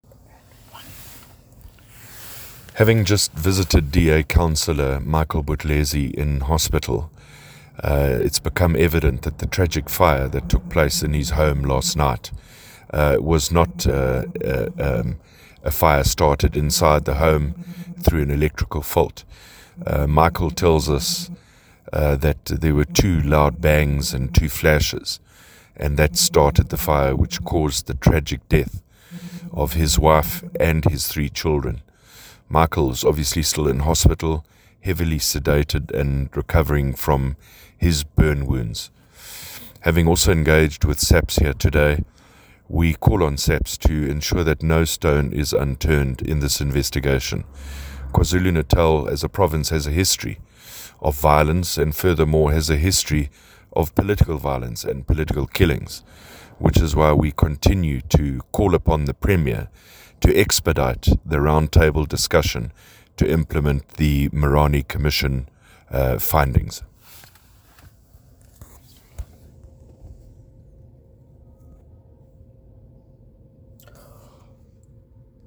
soundbite by Francois Rodgers MPL.